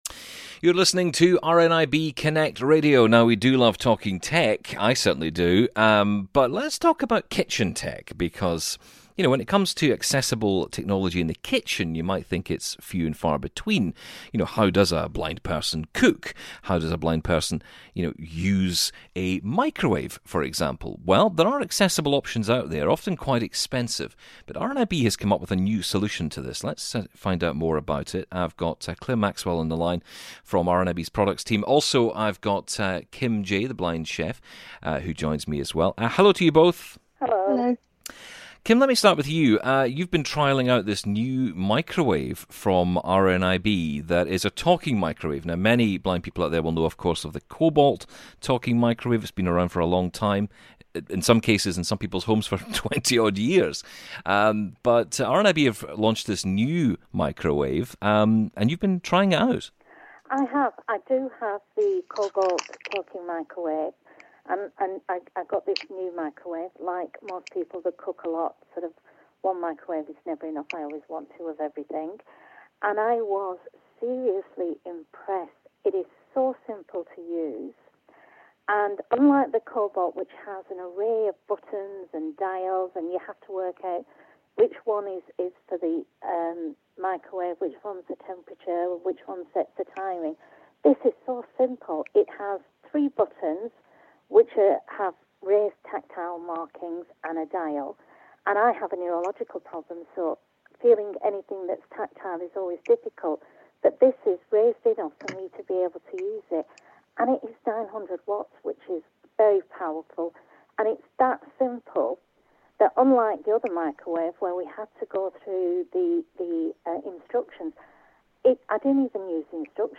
a blind cook who has been testing it out.